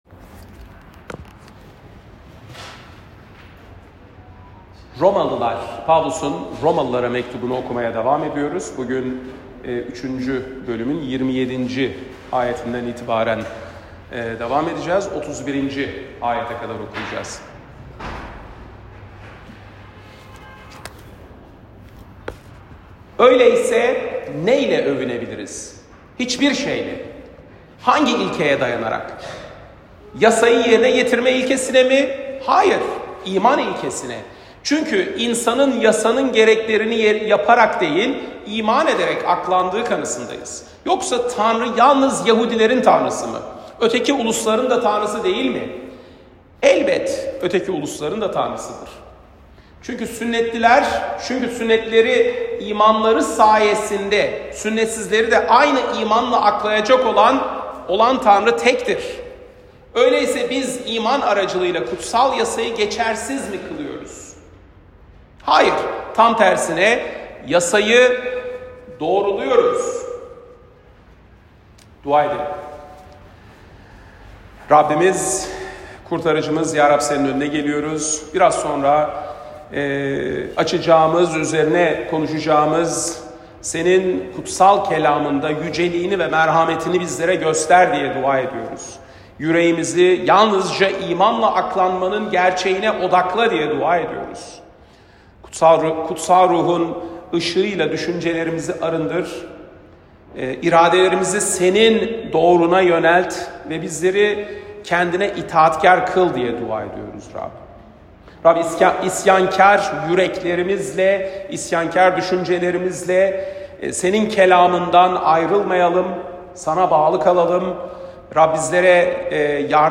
Pazar, 23 Şubat 2025 | Romalılar Vaaz Serisi 2024-26, Vaazlar